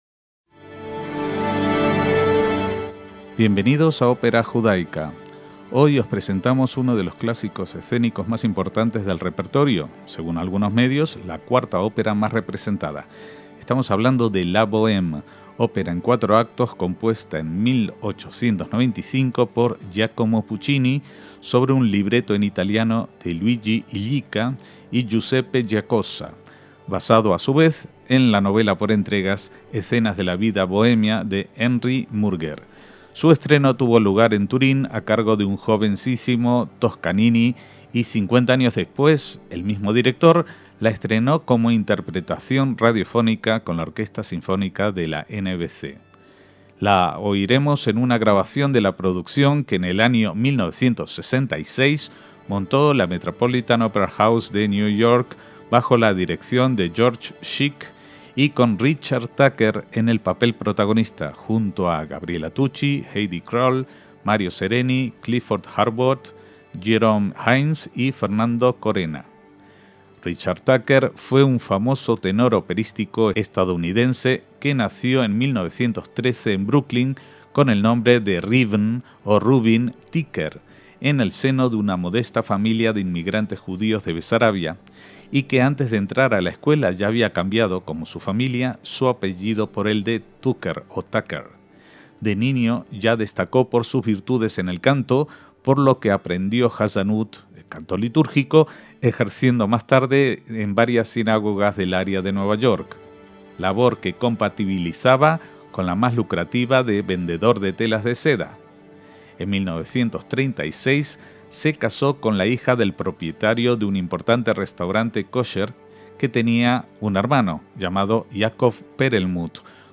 obra en cuatro actos
tenor lírico
La escucharemos en una grabación de 1966 en el Metropolitan Opera House de Nueva York,